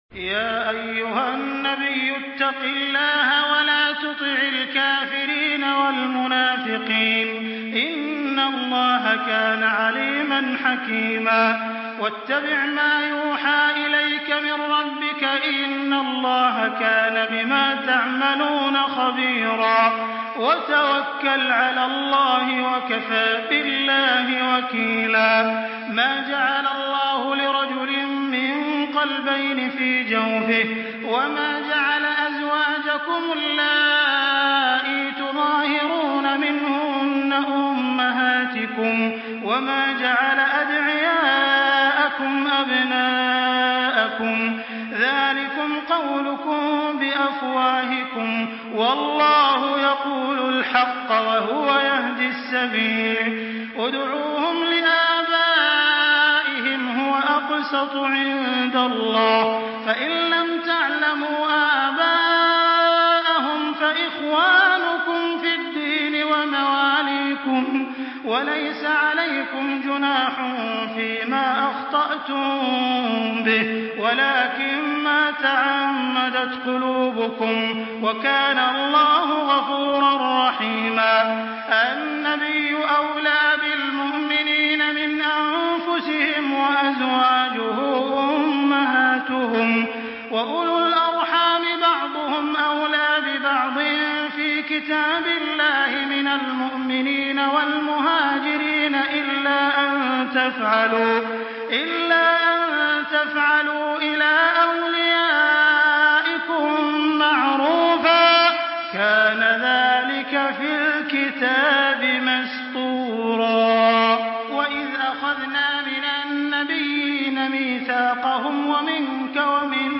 Surah Ahzab MP3 by Makkah Taraweeh 1424 in Hafs An Asim narration.
Murattal Hafs An Asim